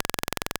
Hum36.wav